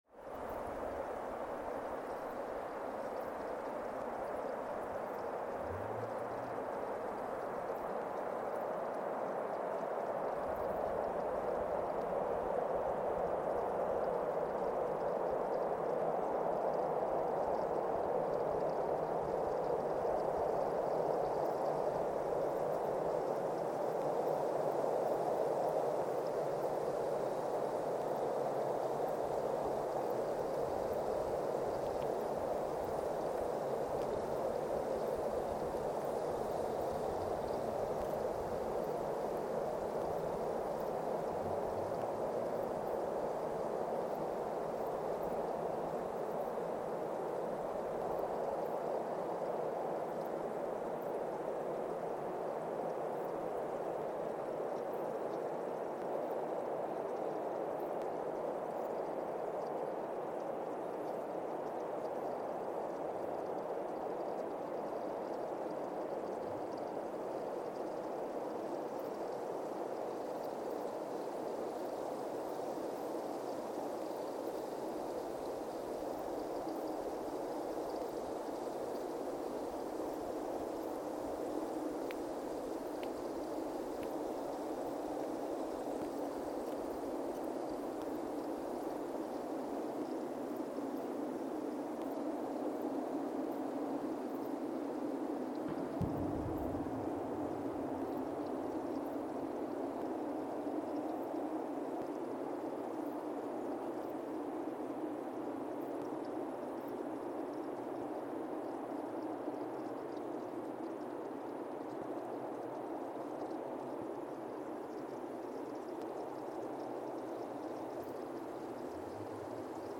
Weston, MA, USA (seismic) archived on December 18, 2024
Station : WES (network: NESN) at Weston, MA, USA
Sensor : CMG-40T broadband seismometer
Speedup : ×1,800 (transposed up about 11 octaves)
Loop duration (audio) : 05:36 (stereo)
Gain correction : 25dB